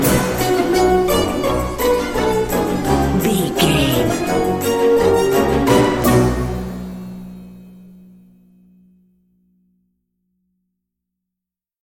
Uplifting
Aeolian/Minor
F#
percussion
flutes
piano
orchestra
double bass
silly
circus
goofy
comical
cheerful
perky
Light hearted
quirky